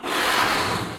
inflate_bloon.ogg